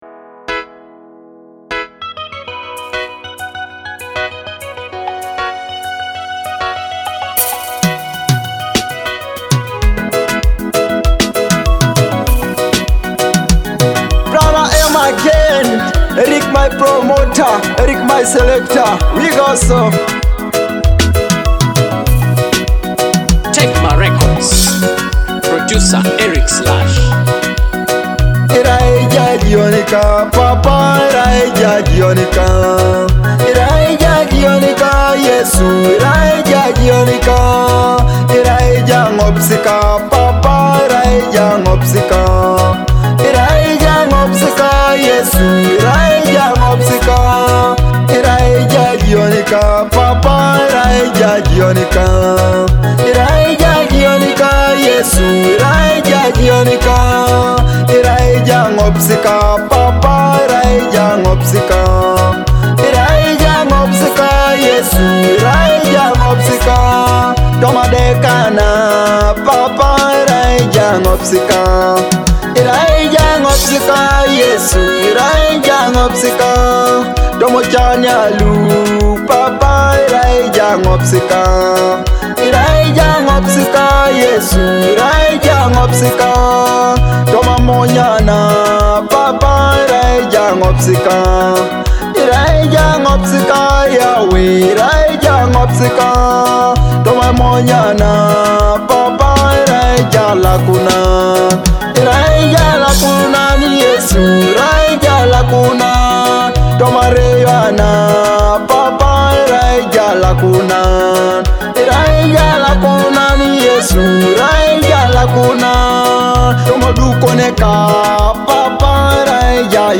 heartfelt Teso gospel worship song